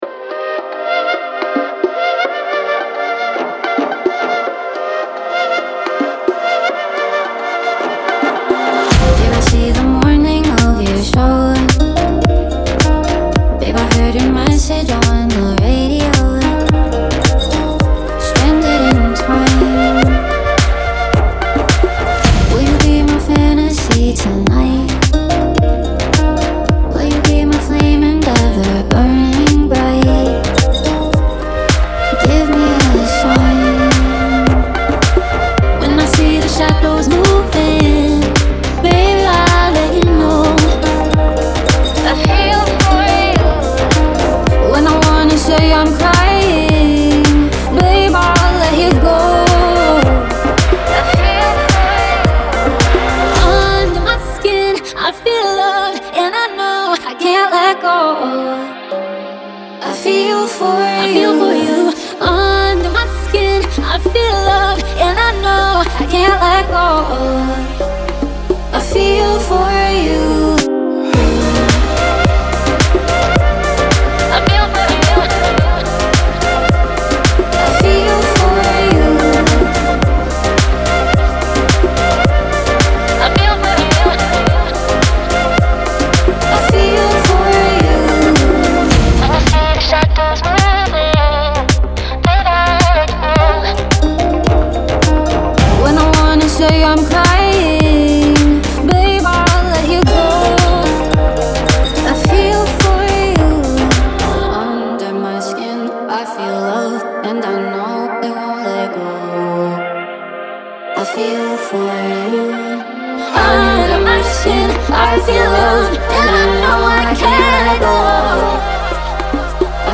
This is a dance style version of the cover (see above).
Tanzbare Version des Covers (siehe weiter oben).